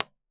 MIT_environmental_impulse_responses
Upload 16khz IR recordings
h037_Classroom_5txts.wav